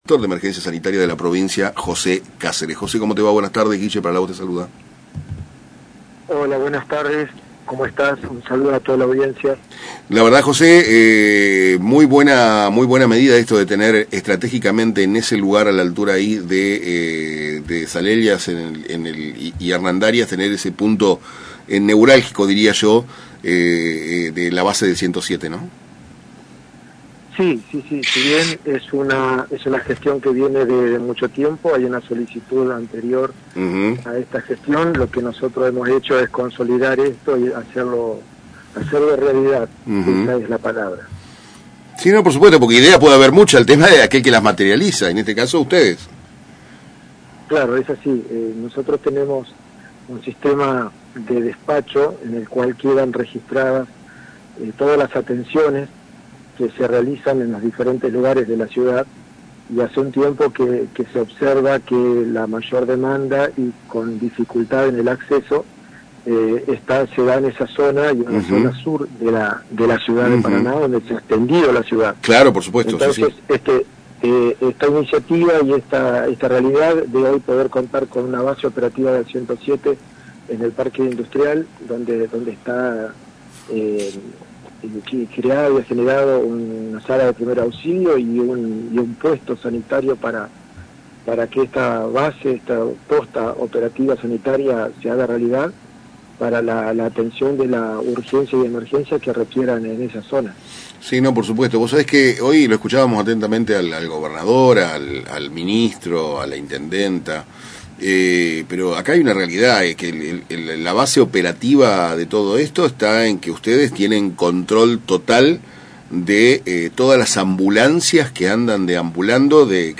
ESCUCHA LA NOTA COMPLETA en Para Temprano es Tarde
Jose Caseres Director de Emergencia Sanitaria de Entre Rios